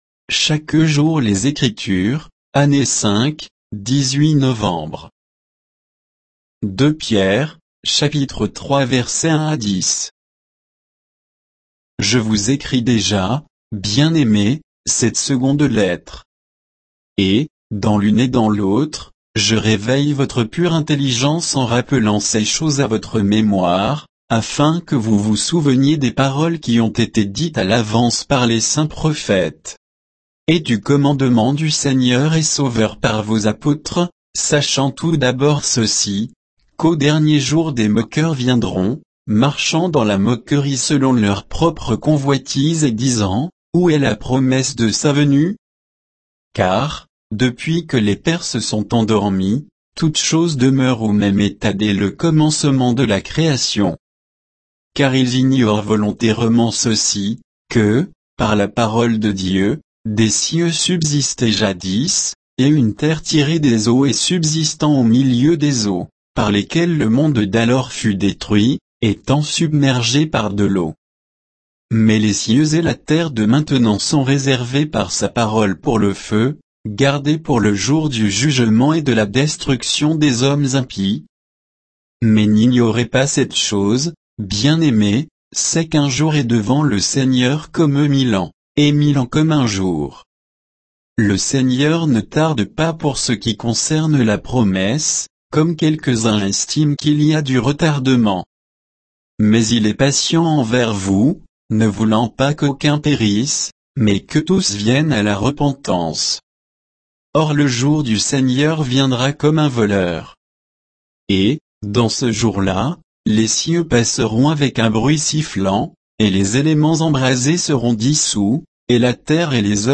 Méditation quoditienne de Chaque jour les Écritures sur 2 Pierre 3